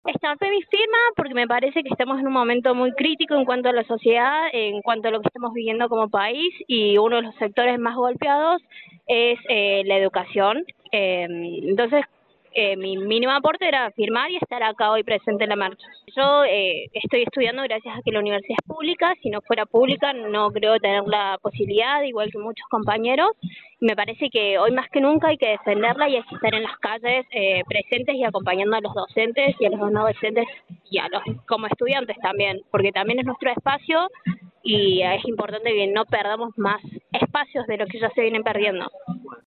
Una estudiante de Trabajo Social de la FCEJS se sumó a la campaña de firmas para adherir al Proyecto de Ley de Financiamiento Universitario “porque estamos en un momento muy crítico en el país y uno de los sectores más golpeados es la educación”, respondió a Radio de la UNSL.